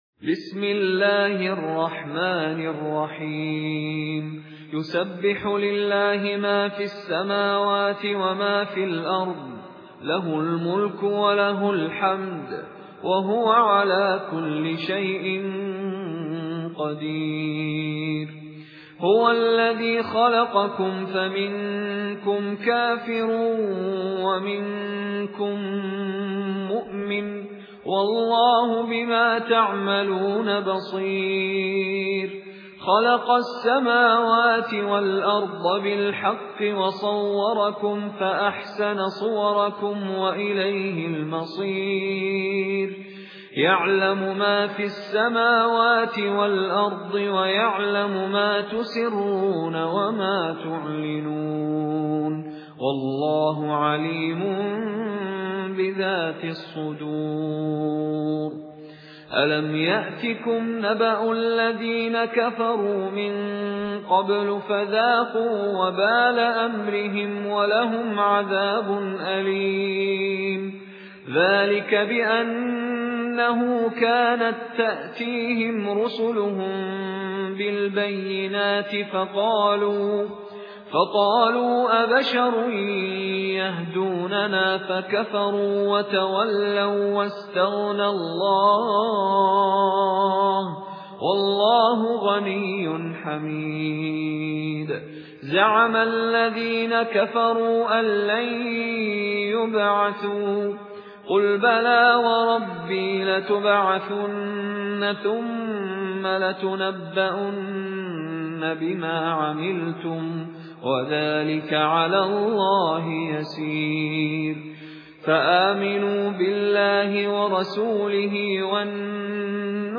مشاري راشد العفاسي ( قصر المنفصل من طريق الطيبة )